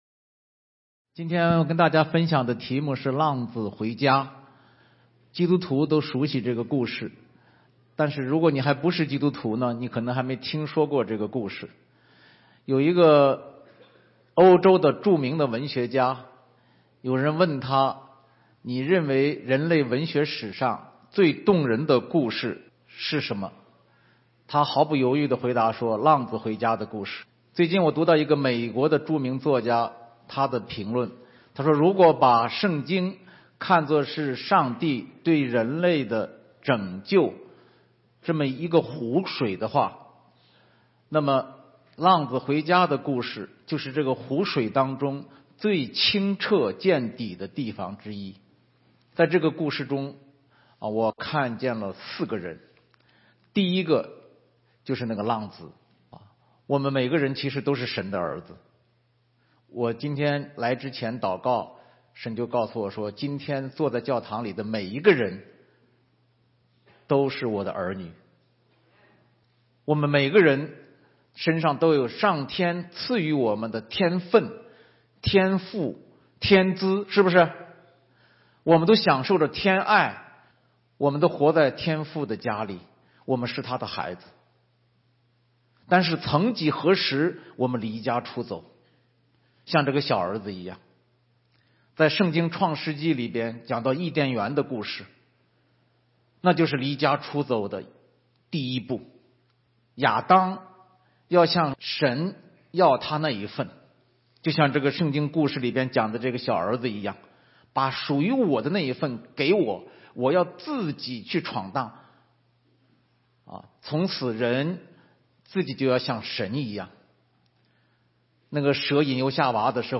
16街讲道录音 - 浪子回家